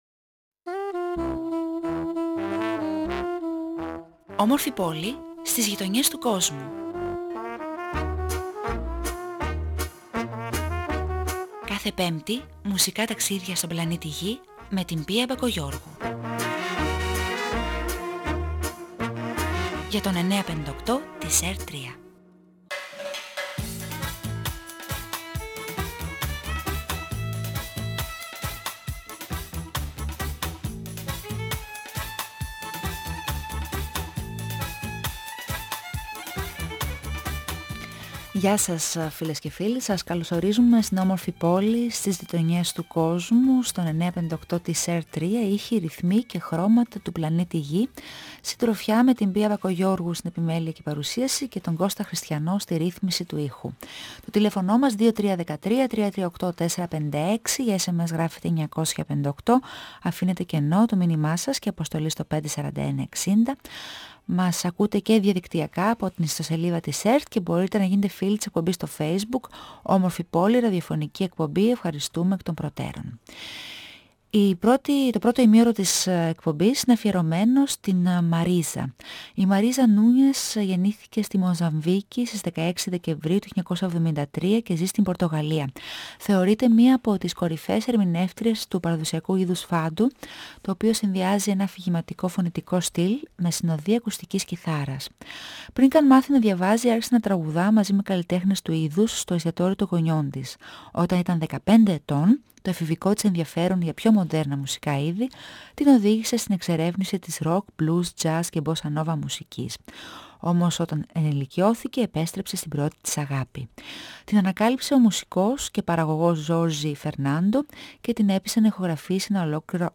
με εντυπωσιακή σκηνική παρουσία και δυνατή, εκφραστική φωνή